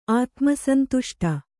♪ ātmasantuṣṭa